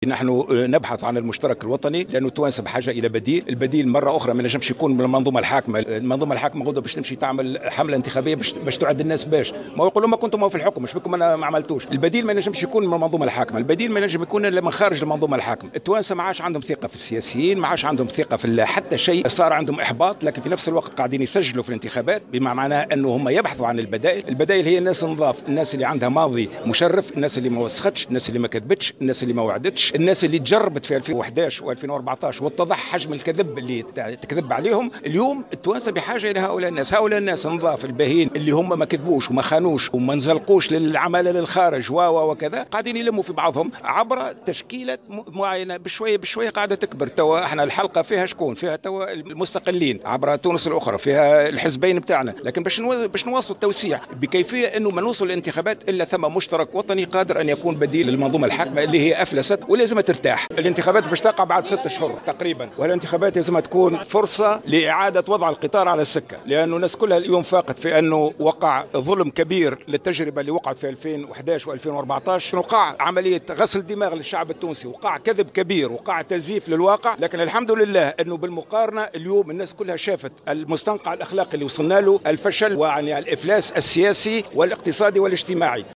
أكد رئيس حزب حراك تونس الإرادة المنصف المرزوقي على هامش ندوة صحفية مشتركة لحزبه...